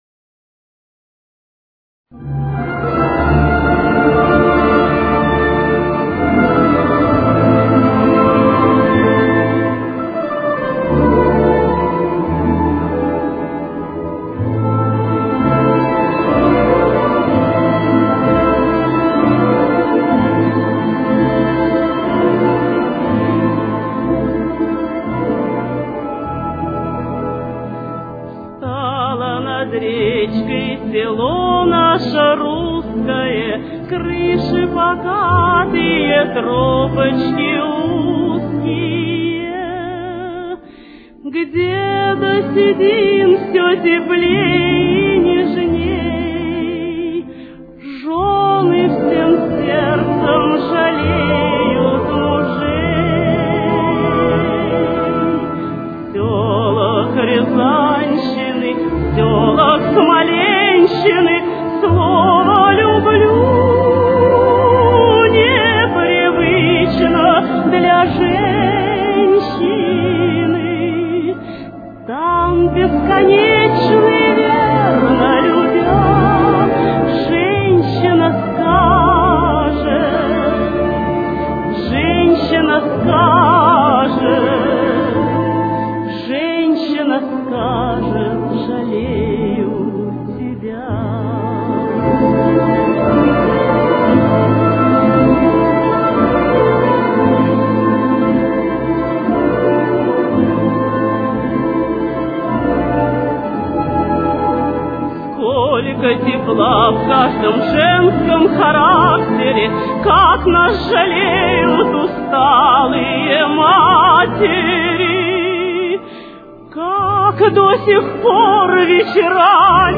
Ля минор. Темп: 50.